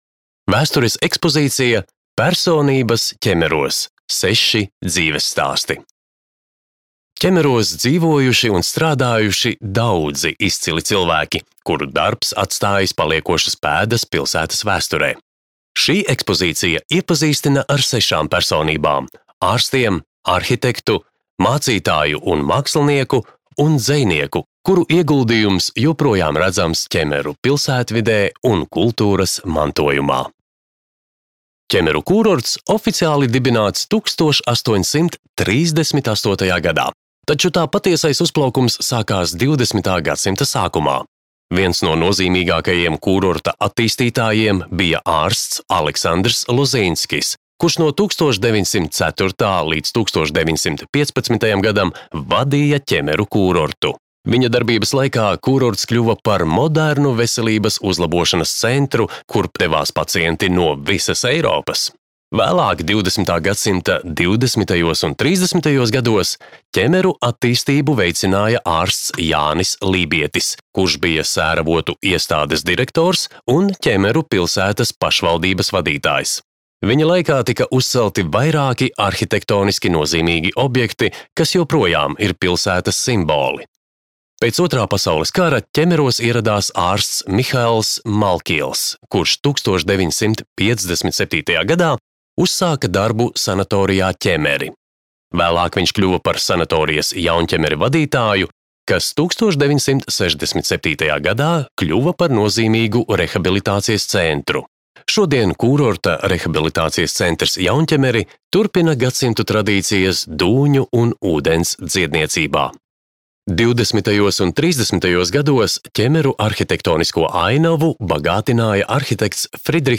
Ķemeru kūrorta parka audiogids